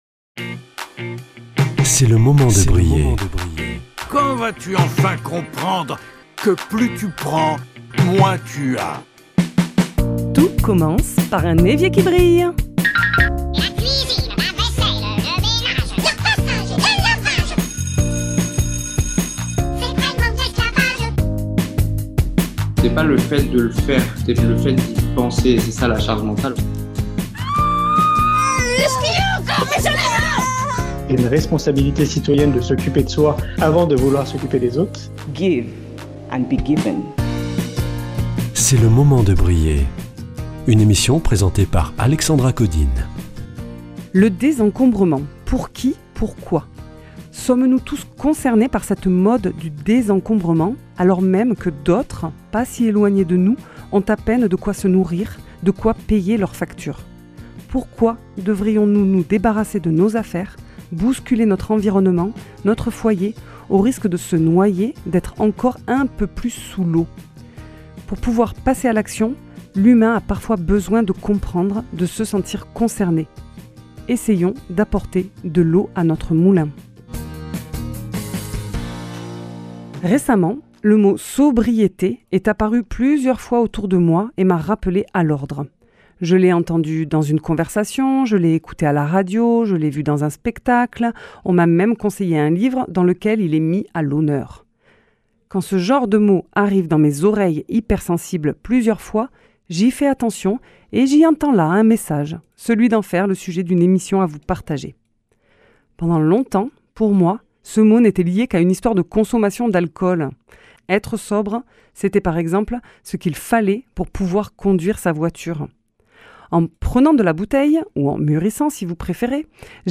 [ Rediffusion ] Sommes-nous tous concernés par cette « mode » du désencombrement ?